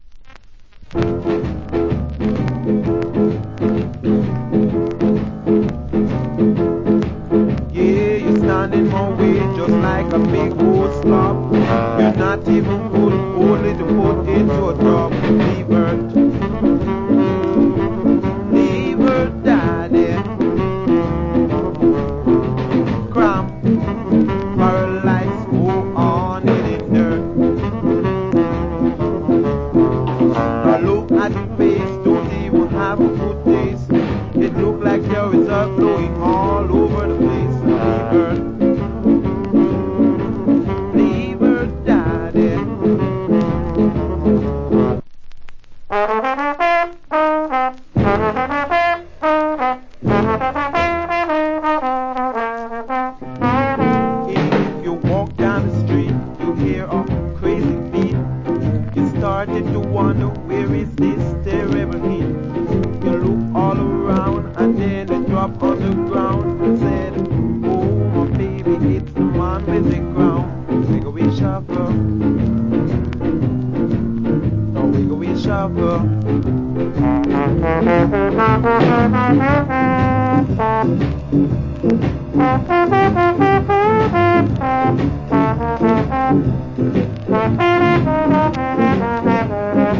Nice Shuffle Vocal.